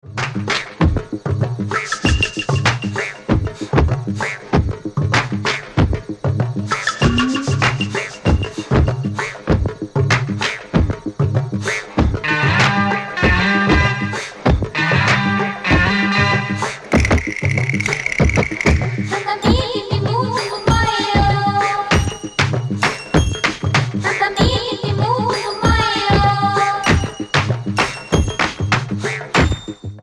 Soulful New Wave & Dubby Rock